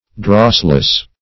Drossless \Dross"less\, a. Free from dross.
drossless.mp3